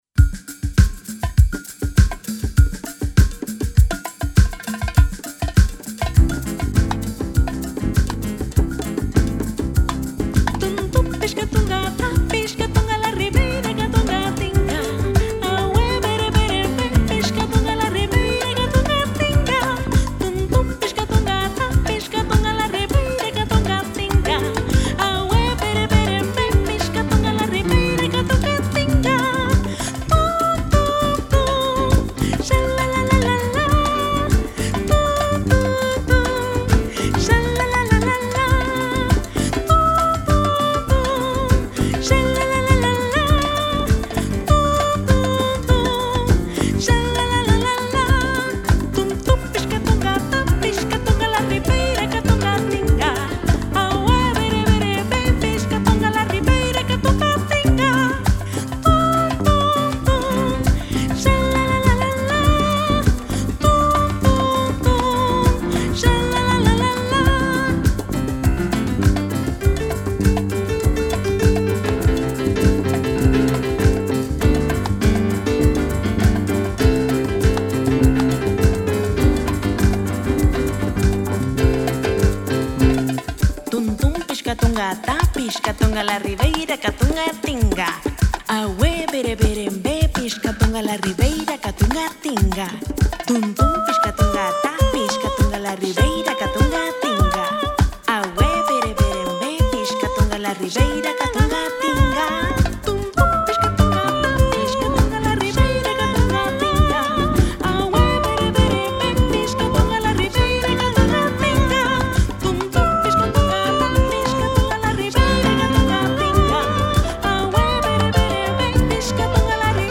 Tradicional brasileña